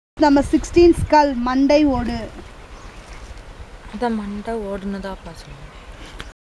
Elicitation of words about human body parts - Part 5